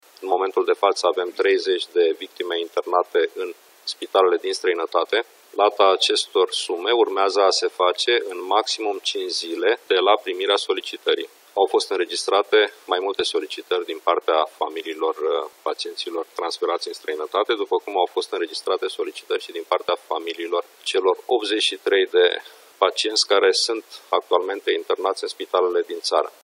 Premierul interimar al României, Sorin Cîmpeanu spune că ajutorul va fi alocat până la externarea pacienților.